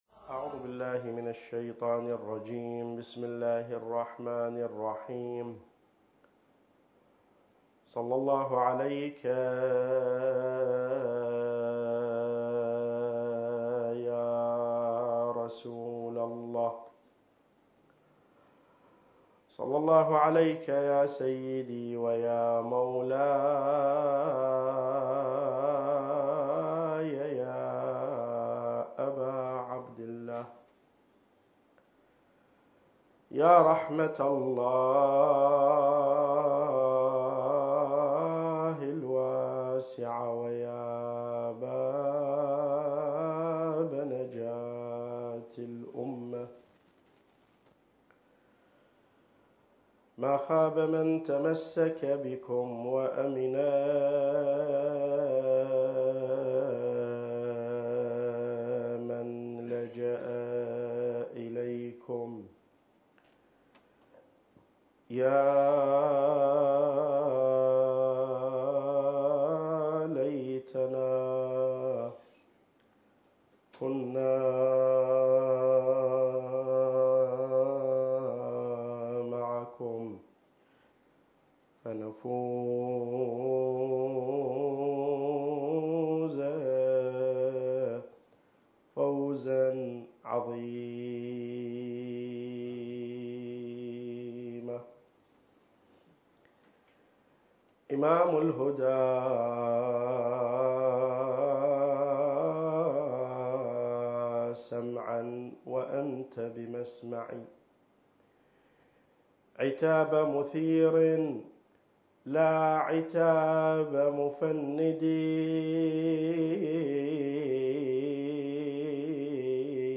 المكان: معهد تراث الأنبياء - النجف الأشرف التاريخ: محرم الحرام 1443 للهجرة